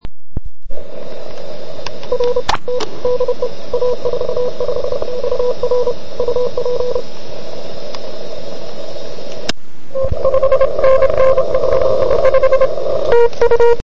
Jak poslouchala FT857?